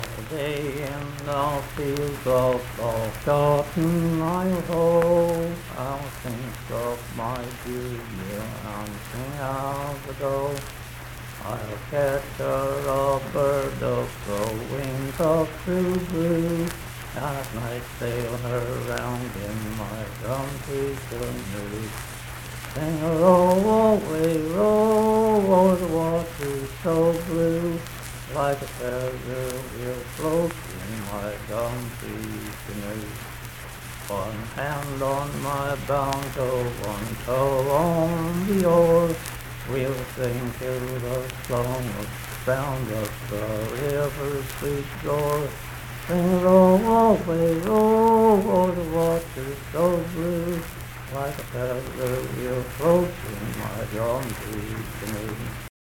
Unaccompanied vocal music
Voice (sung)
Marion County (W. Va.), Mannington (W. Va.)